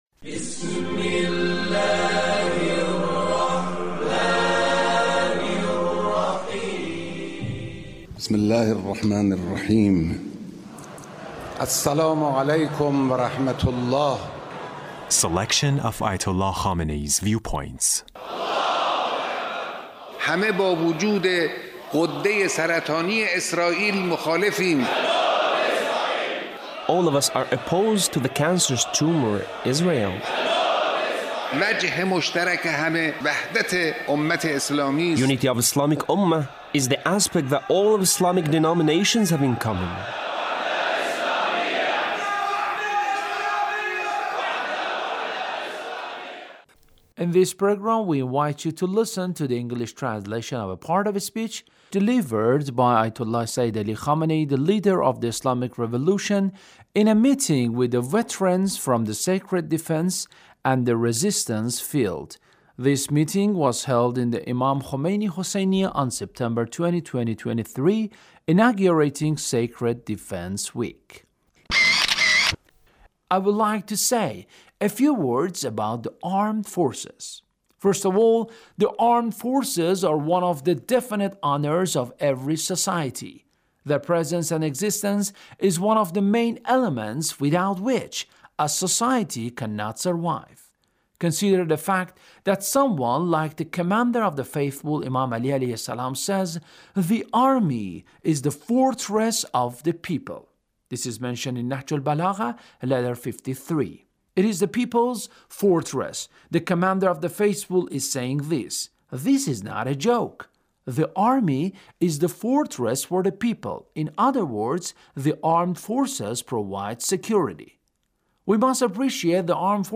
Leader's Speech on Sacred Defense